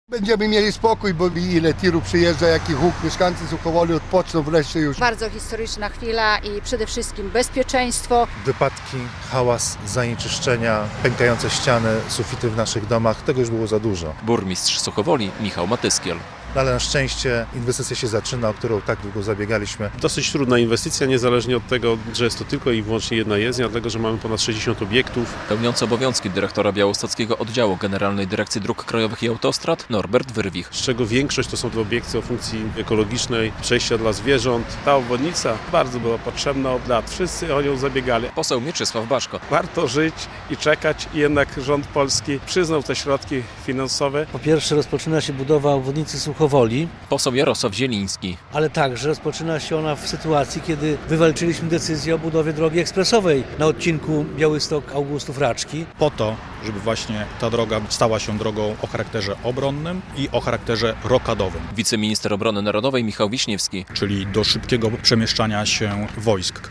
Rozpoczęła się budowa 15-kilometrowej obwodnicy Suchowoli - relacja